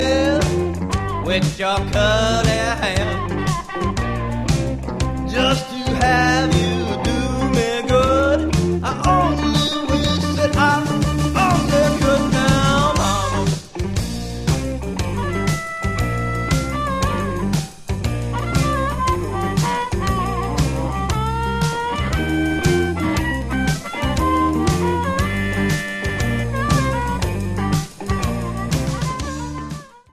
what I’d call a strong “classic” blues vibe